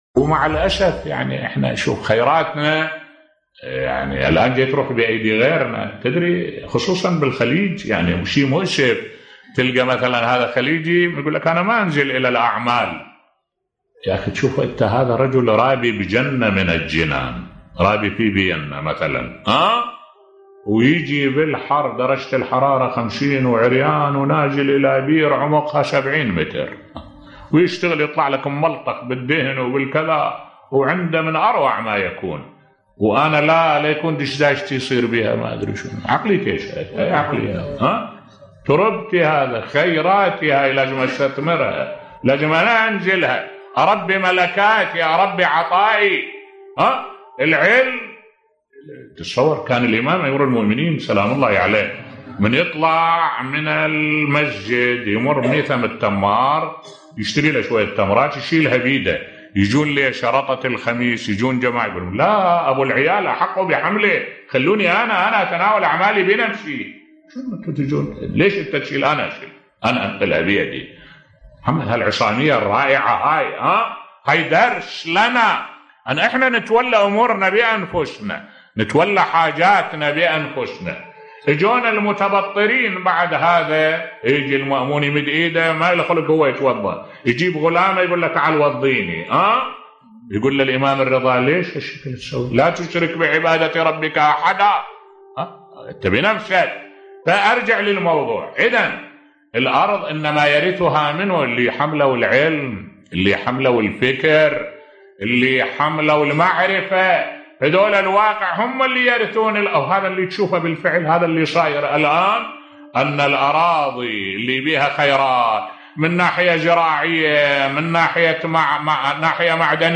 ملف صوتی لماذا يستثمر خيراتنا الغير بصوت الشيخ الدكتور أحمد الوائلي